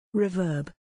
Sound-reverb.mp3